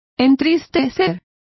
Complete with pronunciation of the translation of darken.